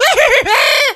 P先生只会像一只企鹅发出无意义的叫声，但是可以从中听出情绪。
Media:mrp_die_vo_02.ogg Mr. P cries
P先生的哀嚎